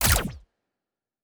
Weapon 04 Shoot 3.wav